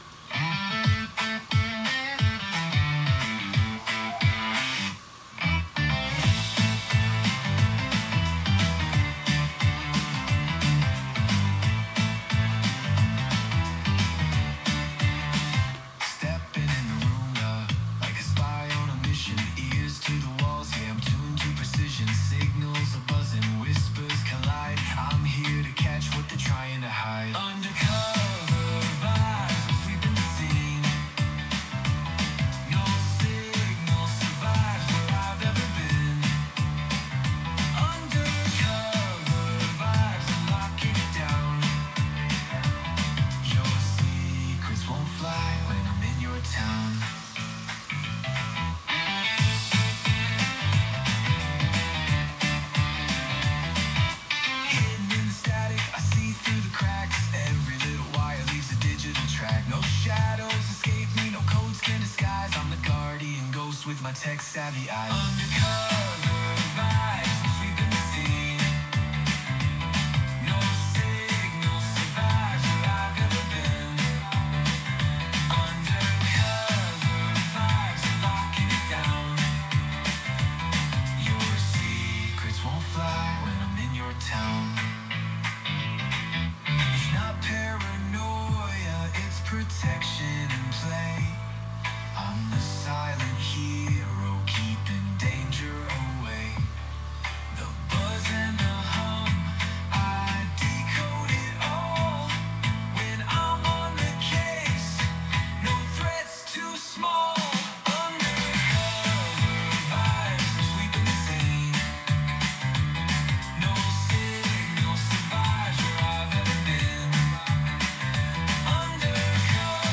Lyrics, music and vocal were all created in 2 minutes, by asking AI, ” Create a song about TSCM inspections.”
AI also suggested the following in order to make the song into a radio play hit: English, Upbeat Pop/Dance-Pop, energetic and accessible, with memorable hooks and singable melodies. Classic verse-chorus-verse-chorus-bridge-chorus structure. Production: polished vocals, punchy drums, bright synths, catchy bass lines.